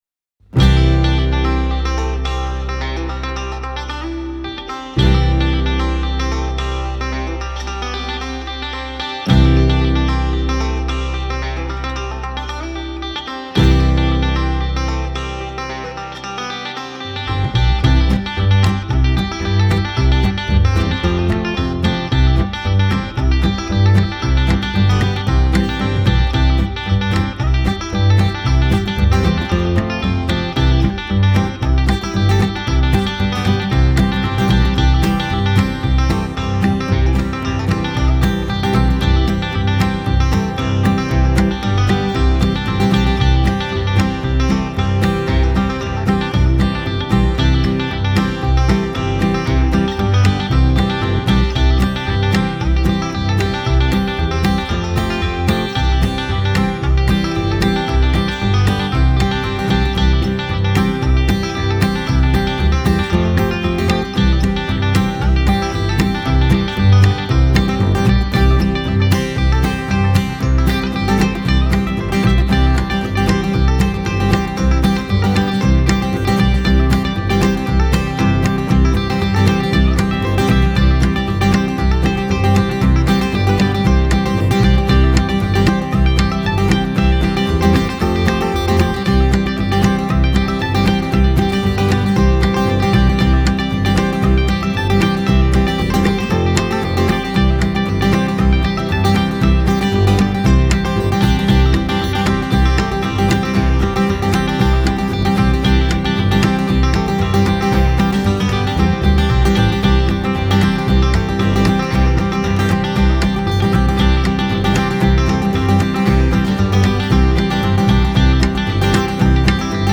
a delightful banjo tune